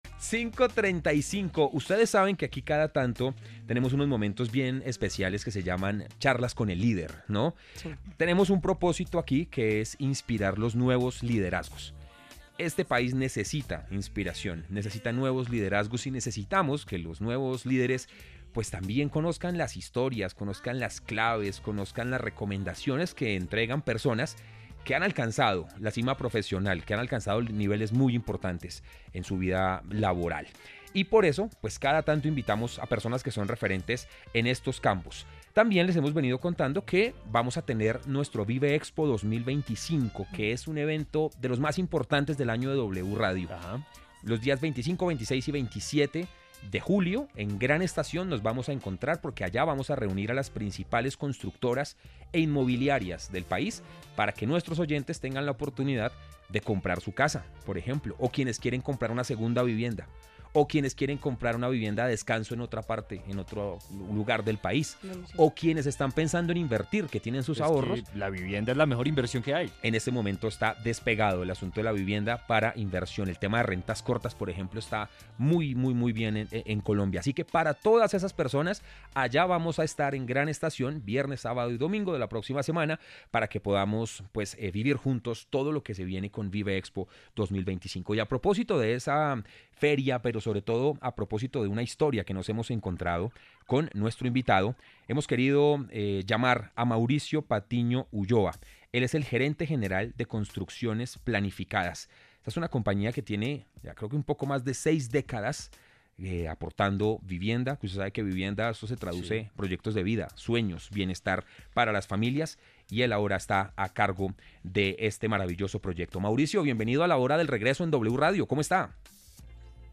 Entrevistas con los líderes